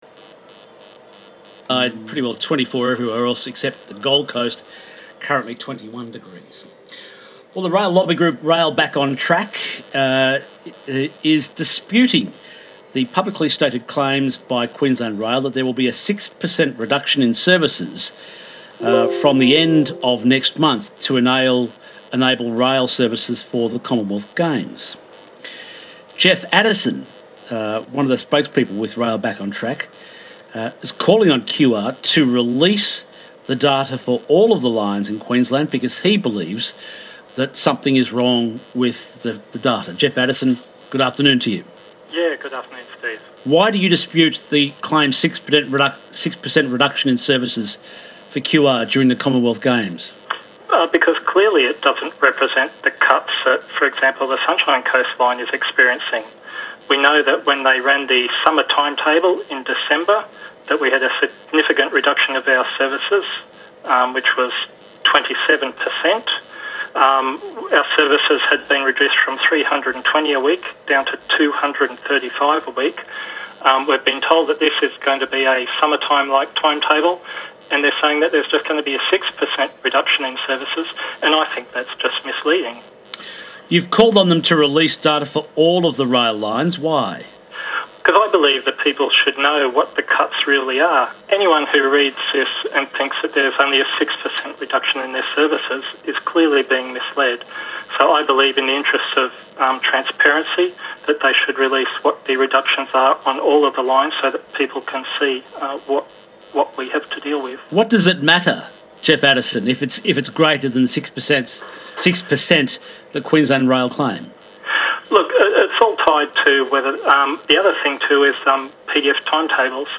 Interview ABC Radio Brisbane